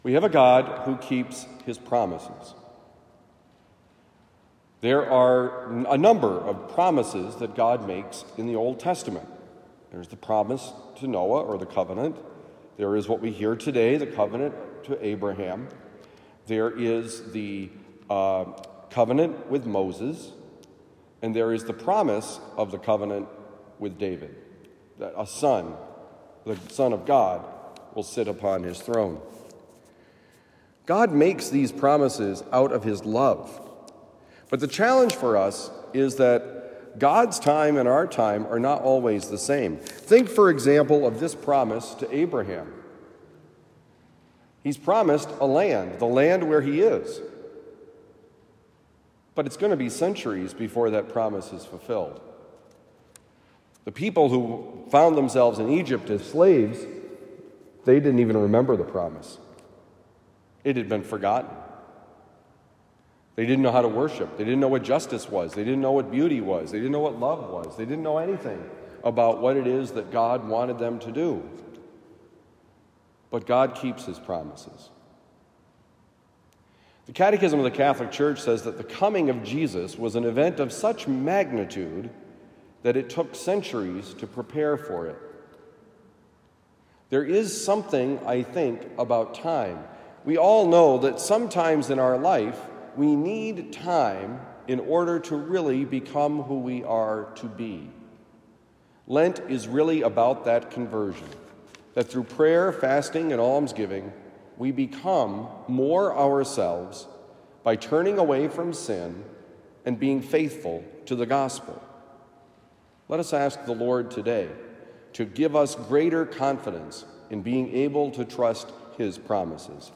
Promises Made, Promises Kept: Homily for Thursday, March 30, 2023
Given at Christian Brothers College High School, Town and Country, Missouri.